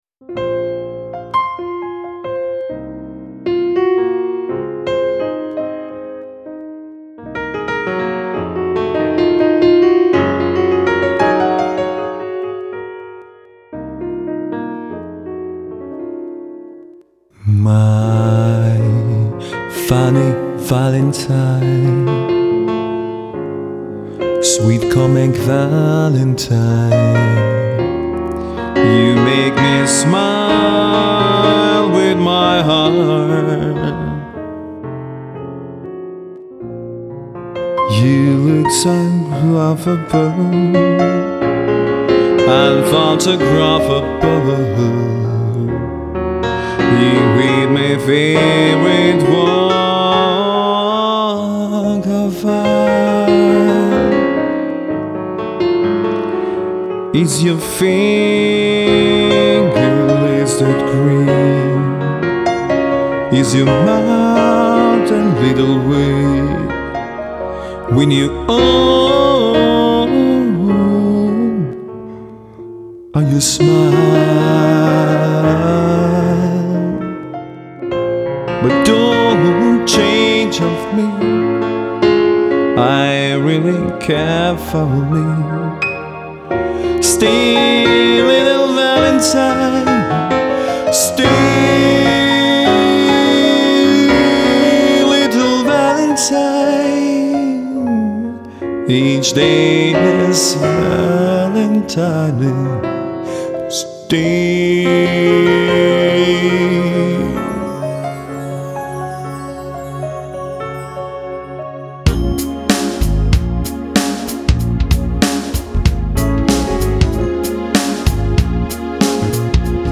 erstklassiger Pianist für Ihre Veranstaltung